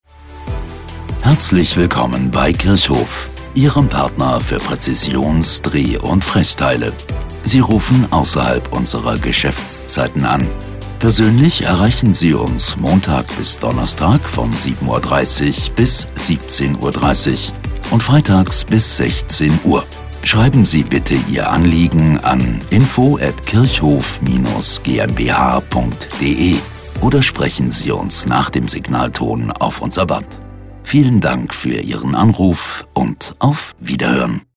Telefonansage Industrie: Anrufbeantworter Ansage außerhalb der Geschäftszeiten:
Kirchhof-AB-Feieraben-1a.mp3